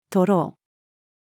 徒労-female.mp3